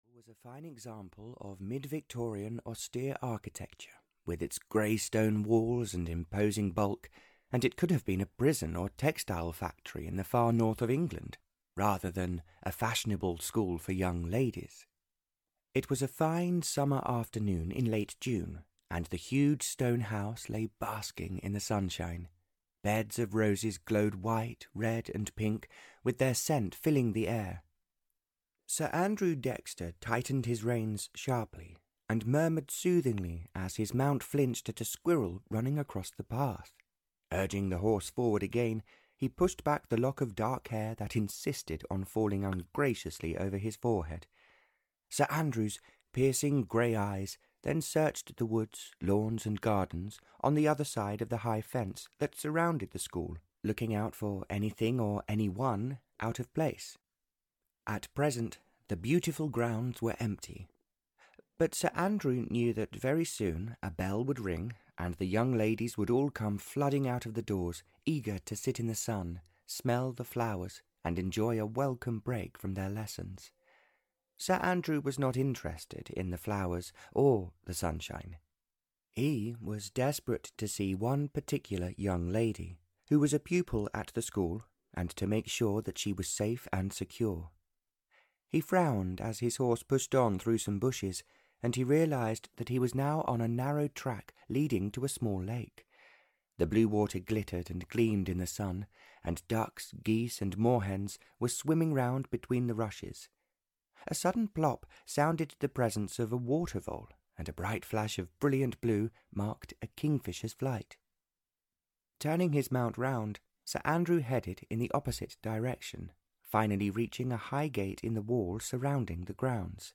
Beyond the Horizon (EN) audiokniha
Ukázka z knihy